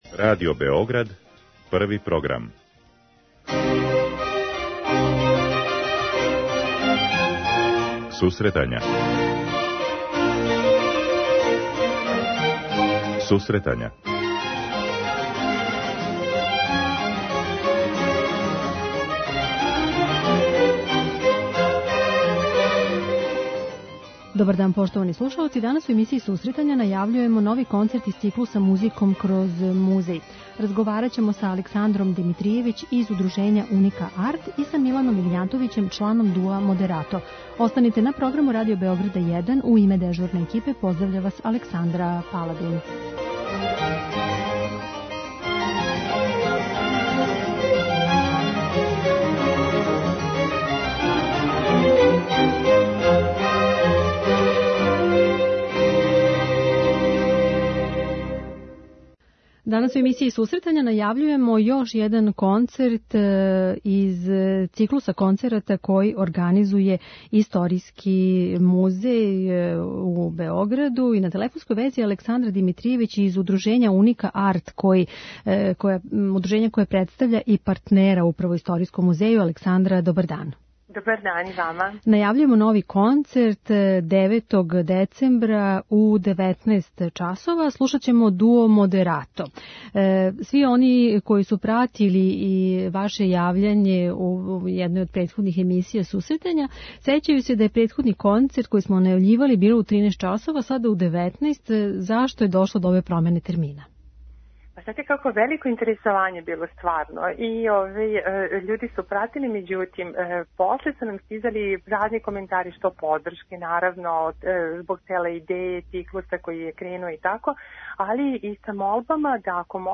Овај ансамбл публици ће се представити интересантним репертоаром стилизованих обрада народне традиције, али и ауторским композицијама. преузми : 10.18 MB Сусретања Autor: Музичка редакција Емисија за оне који воле уметничку музику.